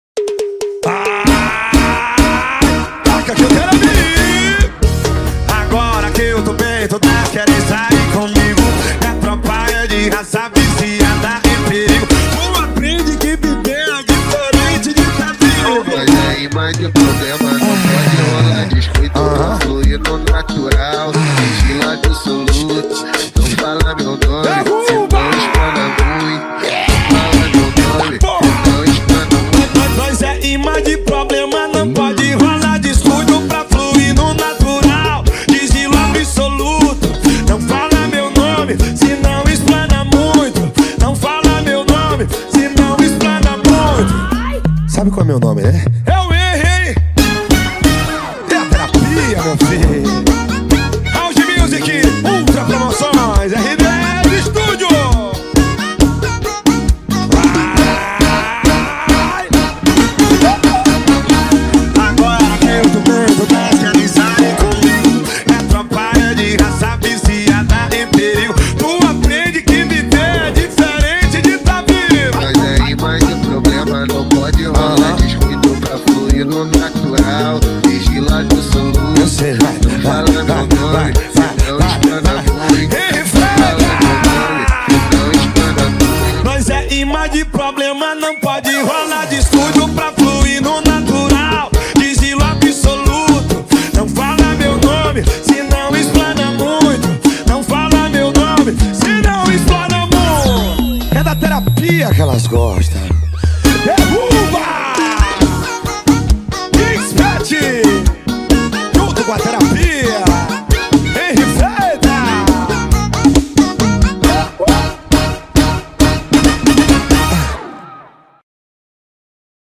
2025-02-16 00:16:59 Gênero: Arrocha Views